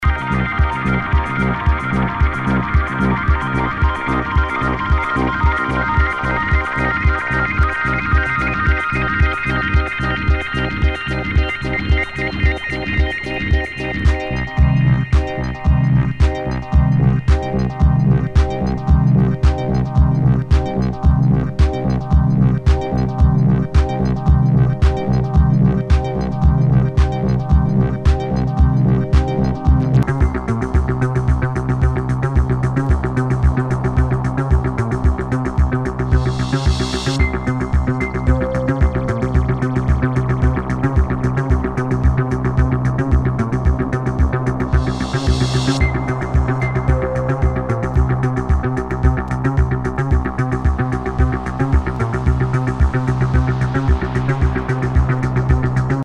HOUSE/TECHNO/ELECTRO